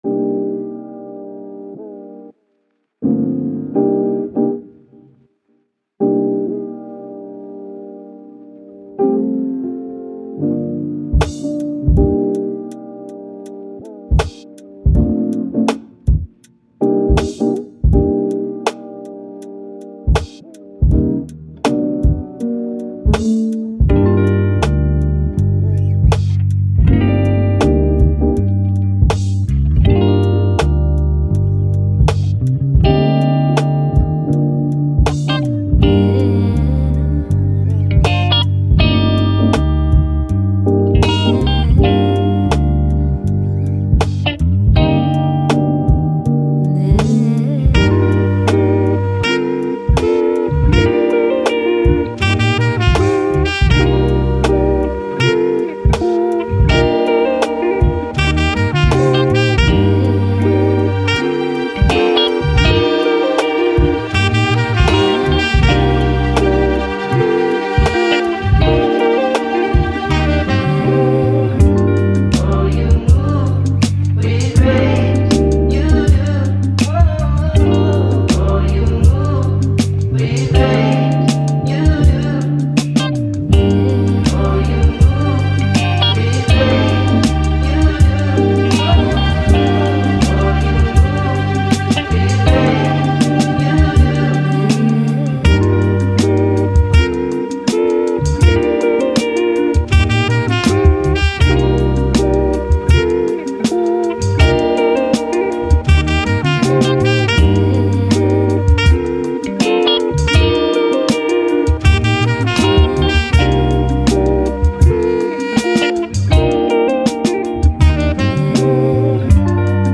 Ambient, lofi, Chill, Chill Hop, Downtempo